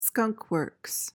PRONUNCIATION:
(SKUNGK-wurks)